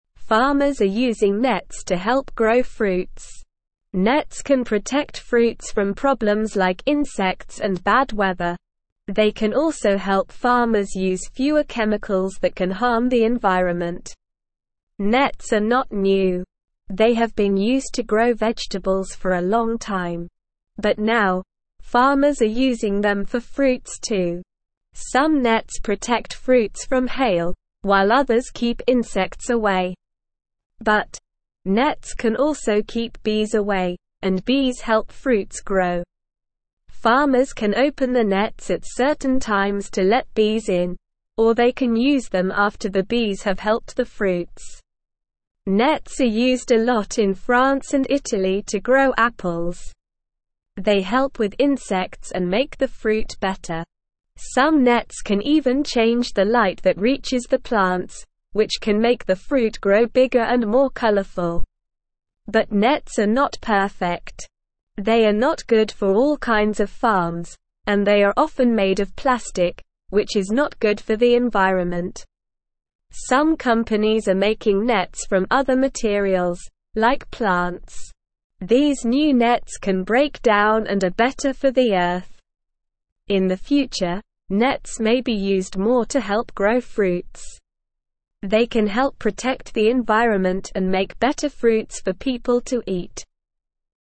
English-Newsroom-Beginner-SLOW-Reading-Farmers-Use-Nets-for-Growing-Fruits.mp3